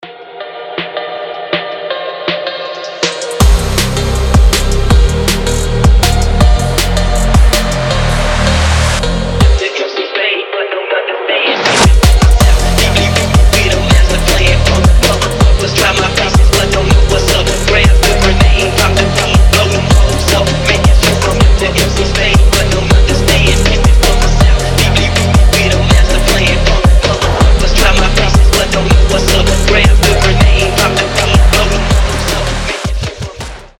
атмосферные
мощные басы
холодные
фонк
По-зимнему морозный фонк